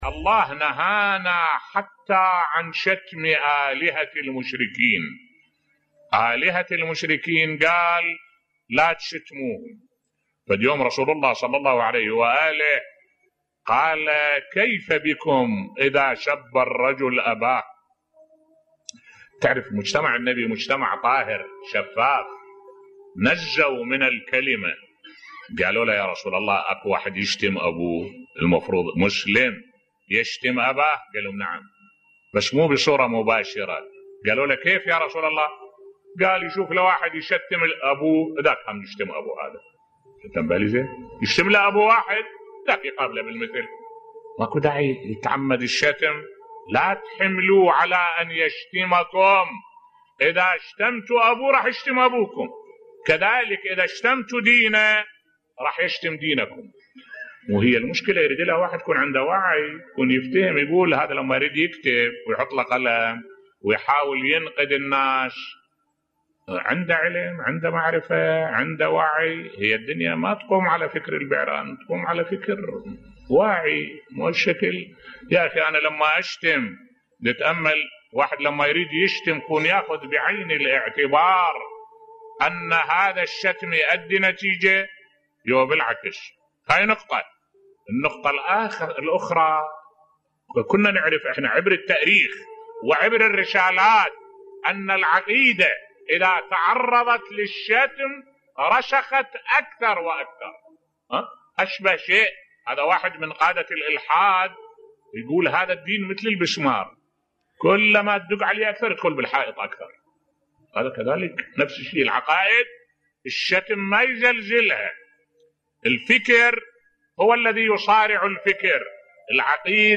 ملف صوتی النيل من عقائد الاخرين يرفضه الاسلام بصوت الشيخ الدكتور أحمد الوائلي